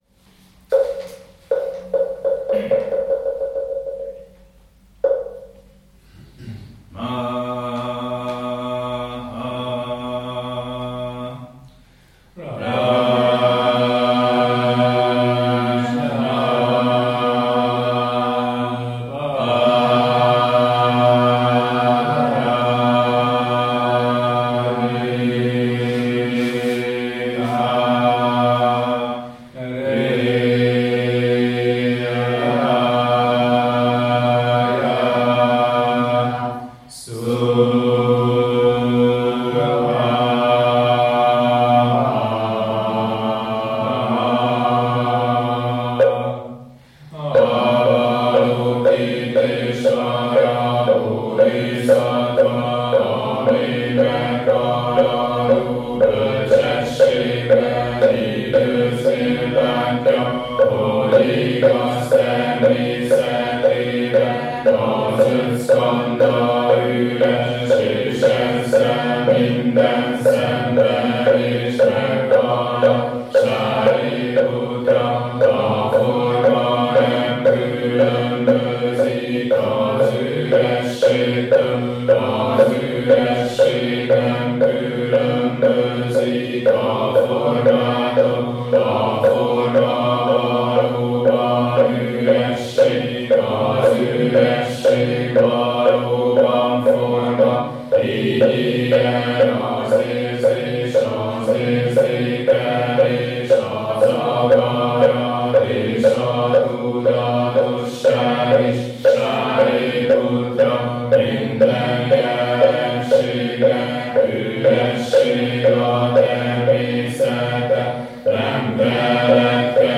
Letöltések - Meditációs énekek
Az alábbi linkekkel letöltheted énekeskönyvünket és a rendszeres gyakorlatunk során recitált énekeket: